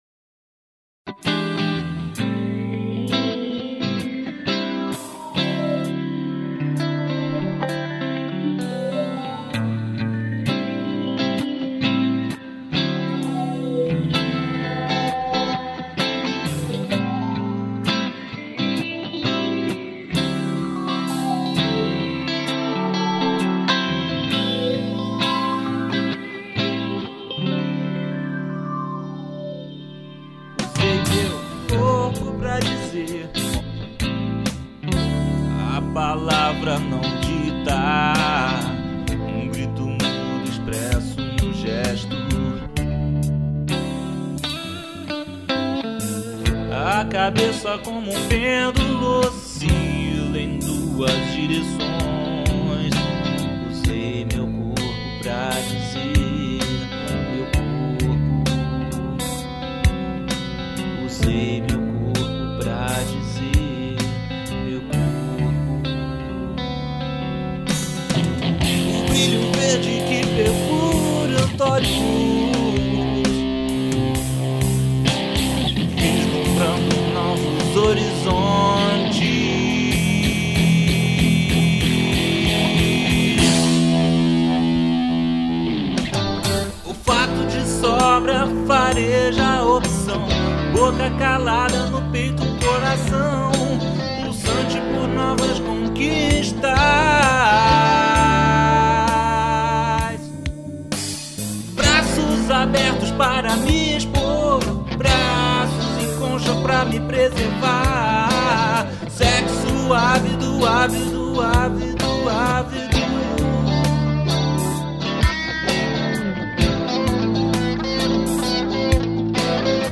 voz, guitarras, baixo e bateria eletrônica